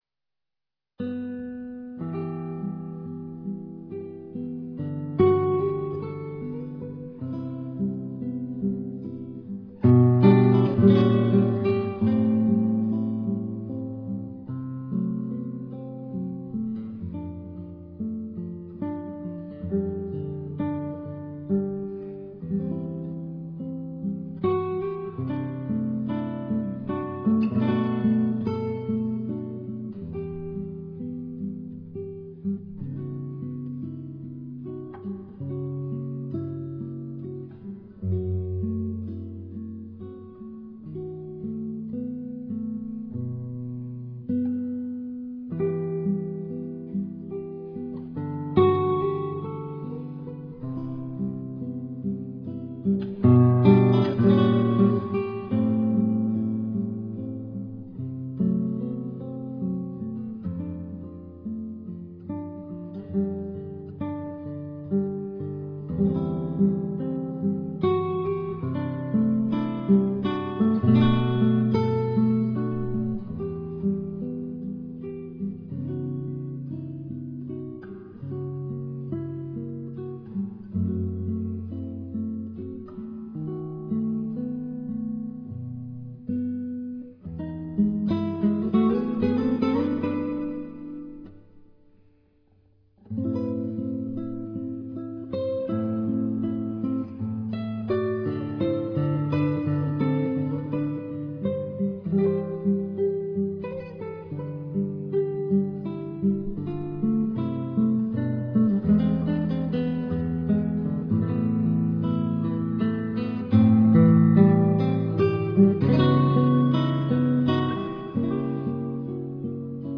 Tuning: EADGBE Key : E Minor/Major